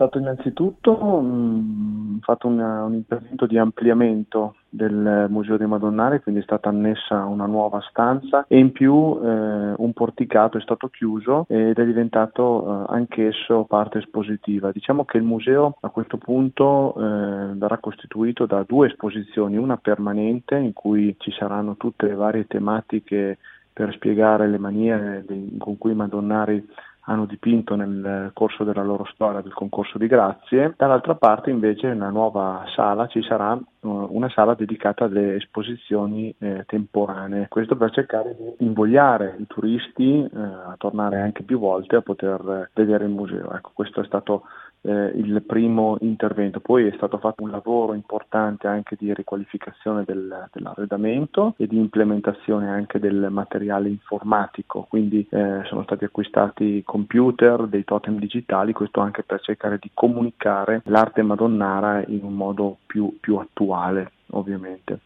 Tutte le novità nelle parole dell’Assessore alla Cultura, Federico Longhi: